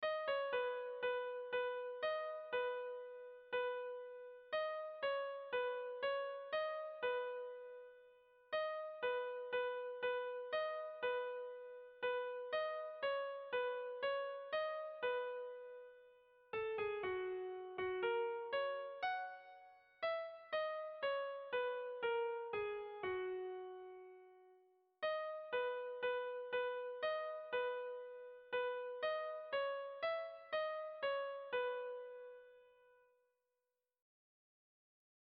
Kontakizunezkoa
Zortziko txikia (hg) / Lau puntuko txikia (ip)
A-A-B-A